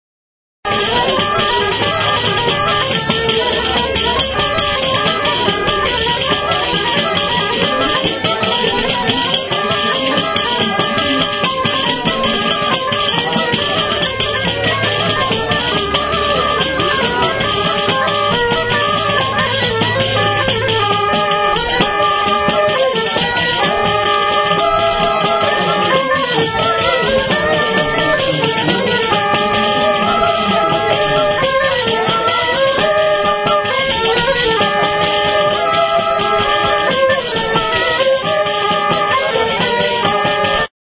Aerófonos -> Lengüetas -> Simple (clarinete)
JOTA.
EUROPA -> EUSKAL HERRIA
ALBOKA